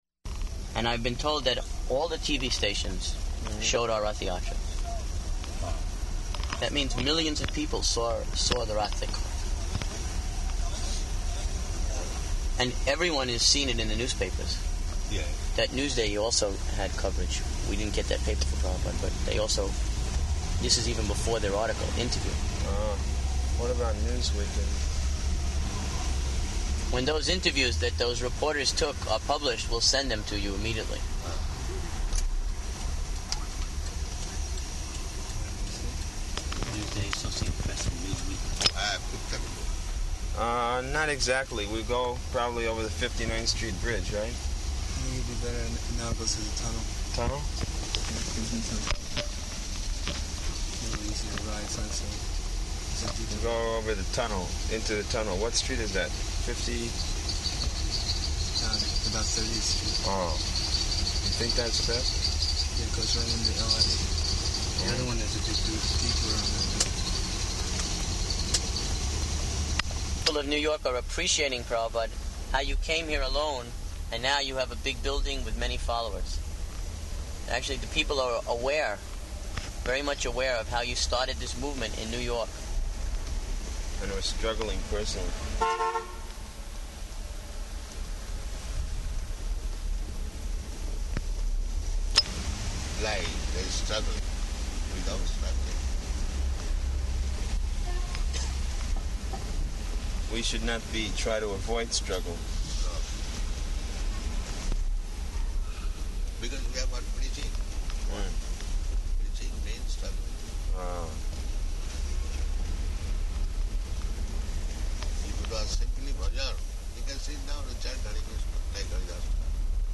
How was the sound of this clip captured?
Car Conversation Location: New York